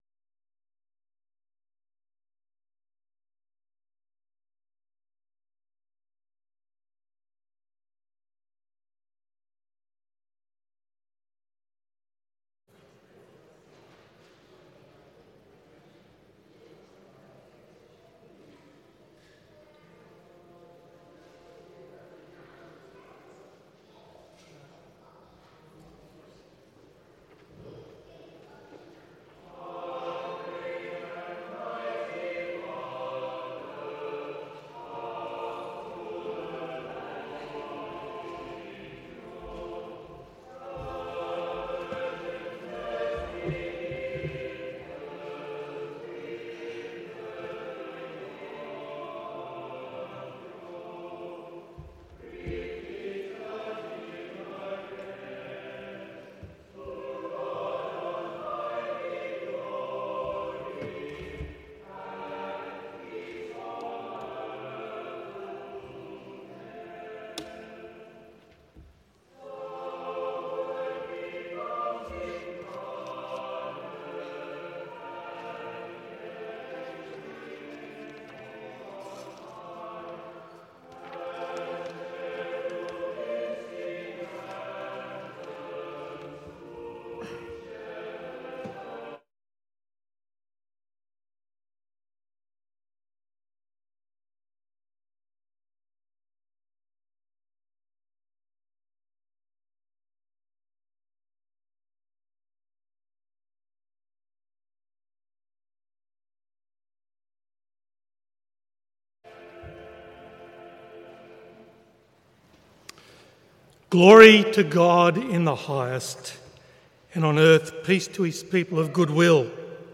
Christmas Day Service 25 December 2022
Full Service Audio